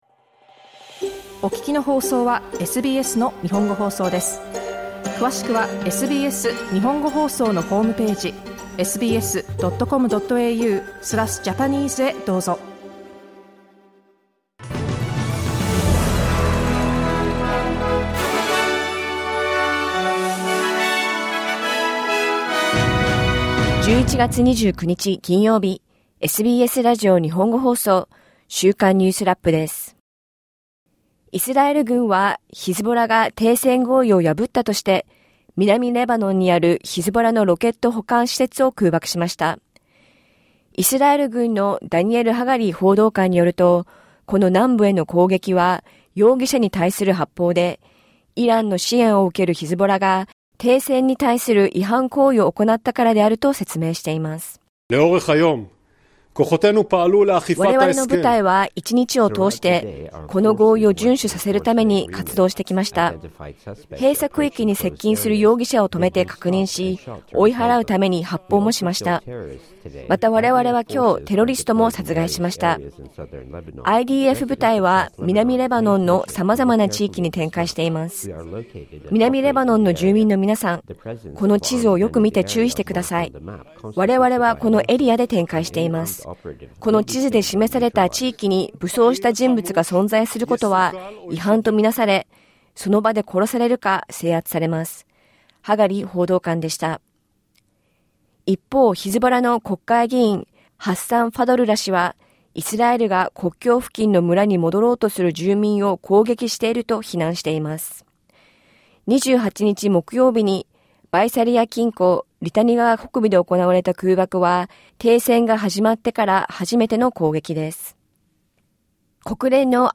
イスラエルとヒズボラは、停戦の合意を違反したとして、互いに非難しています。 上院で、移民法案や16歳未満のSNS禁止法案を含む数十件の法案が可決されました。1週間を振り返る週間ニュースラップです。